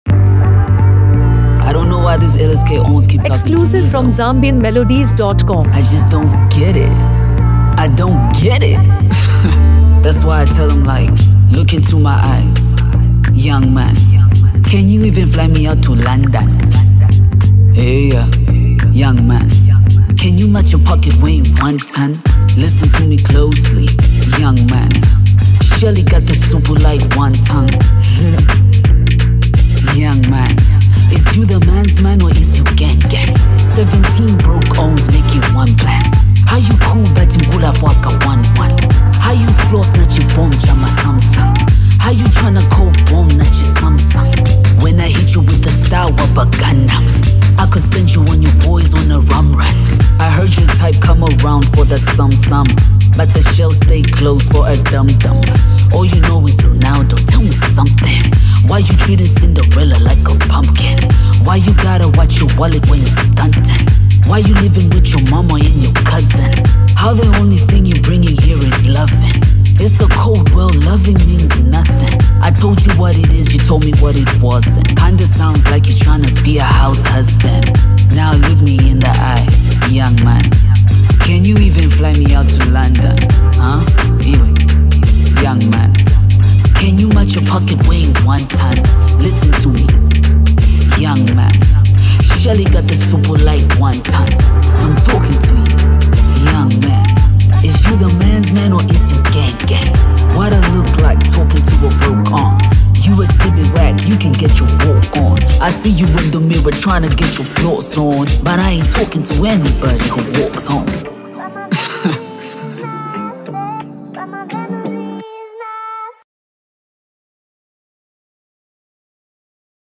a blend of hard-hitting beats and thought-provoking lyrics